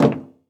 added stepping sounds
MetalSteps_02.wav